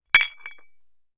Metal_44.wav